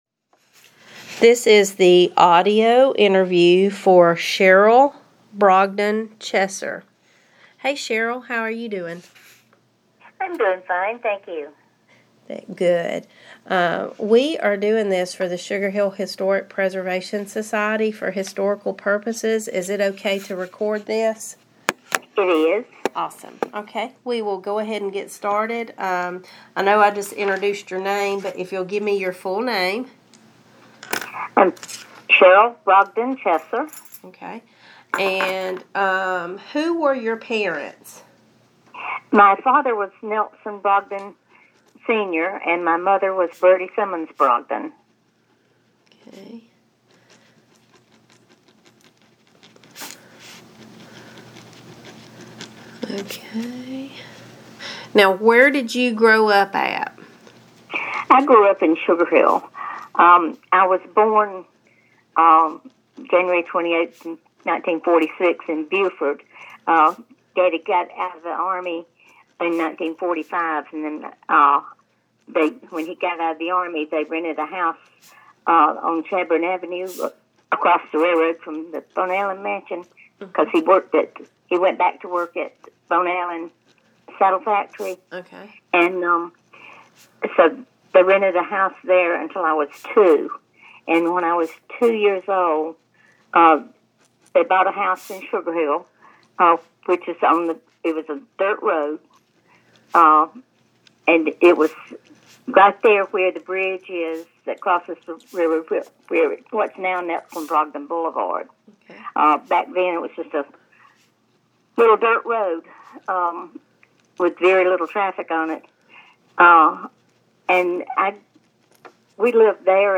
Oral histories Sugar Hill (Ga.)
via telephone